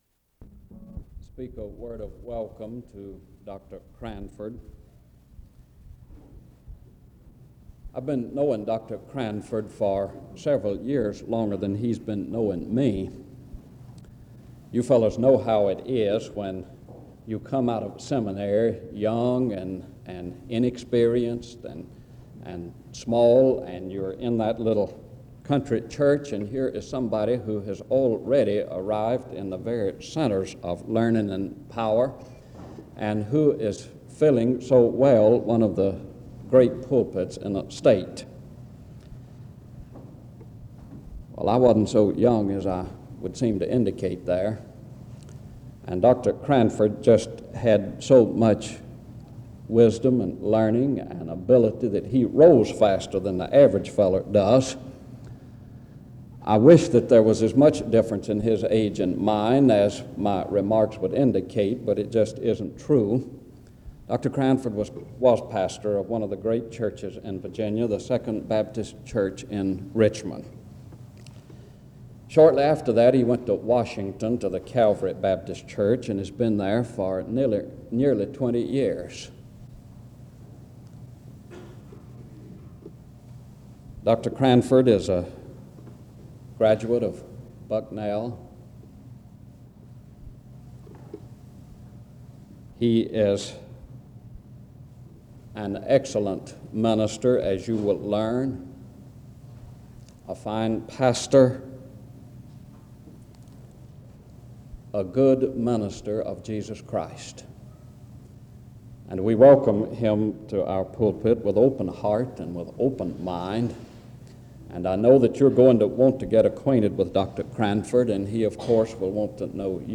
D.C. The service starts with an introduction to the speaker from 0:00-2:15.